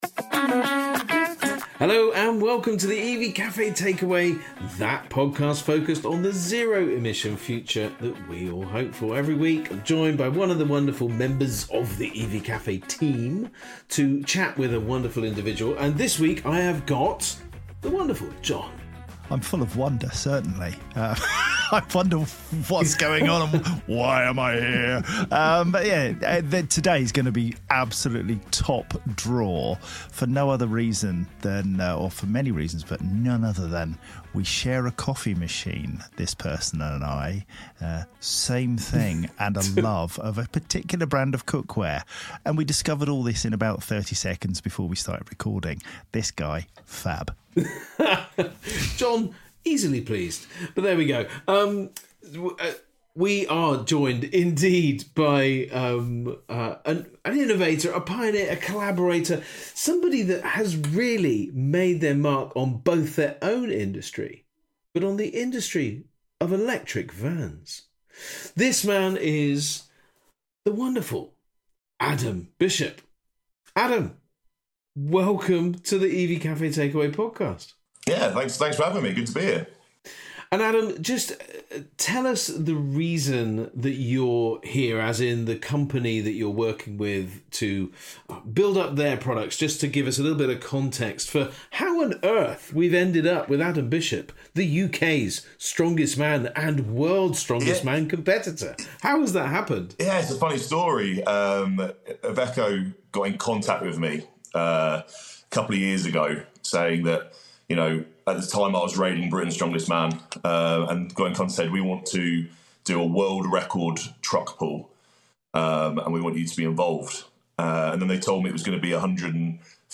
On The Pull: A Conversation With Adam Bishop